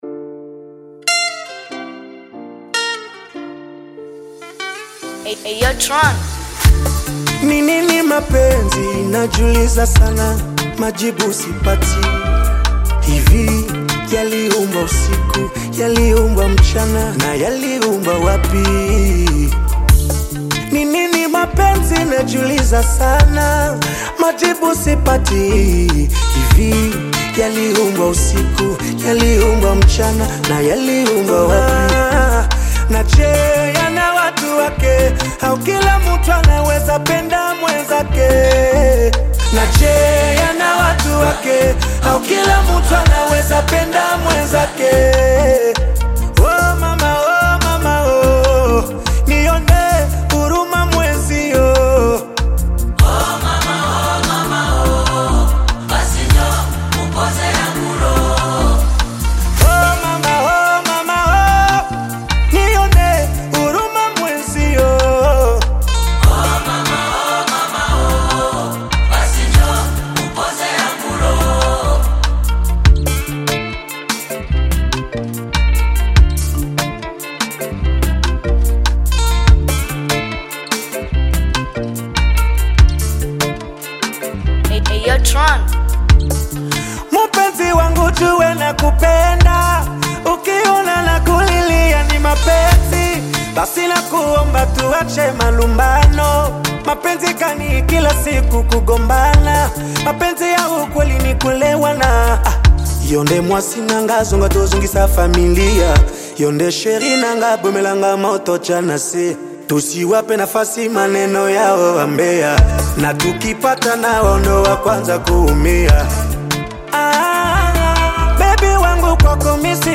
known for his silky smooth voice and emotive performances
Bongo Flava
traditional Congolese Rhumba rhythms